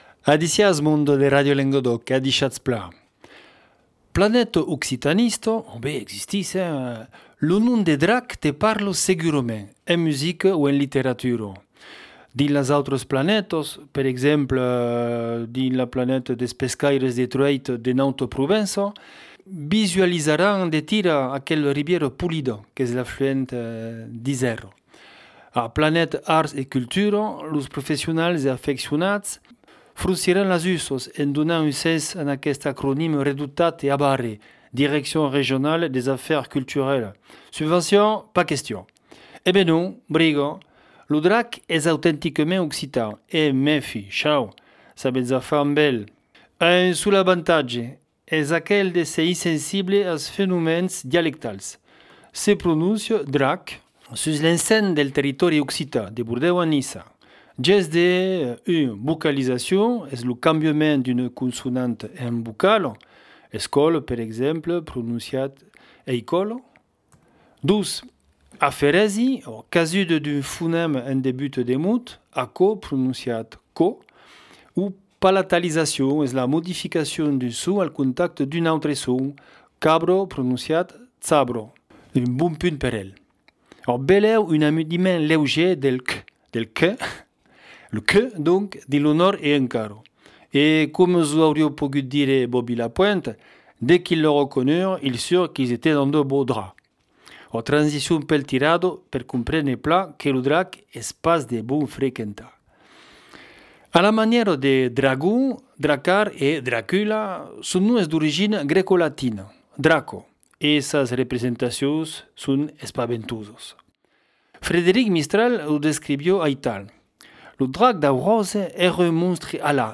Una cronica